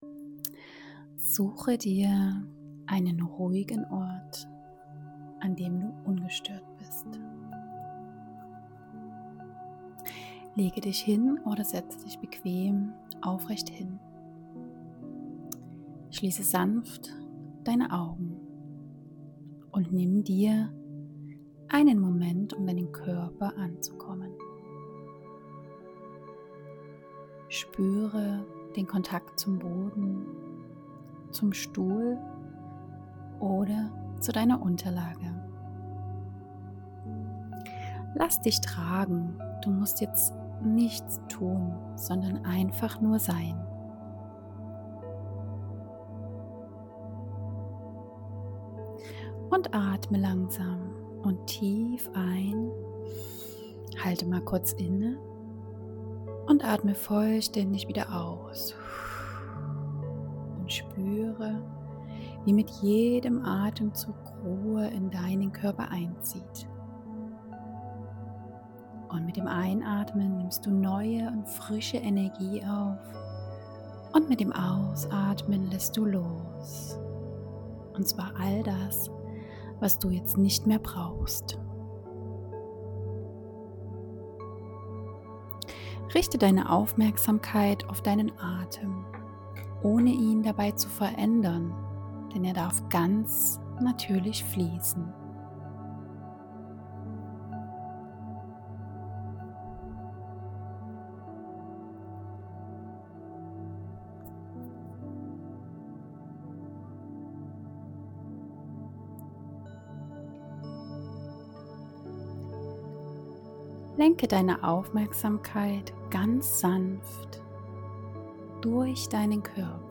In dieser geführten Meditation begleite ich dich auf eine Reise zu innerer Ruhe und Leichtigkeit. Gemeinsam lösen wir Altes, das dich beschwert, und öffnen Raum für Vertrauen – mit dem Gefühl: Alles ist gut, so wie es ist. Eine Einladung zum Loslassen, Ankommen und tiefen Entspannen.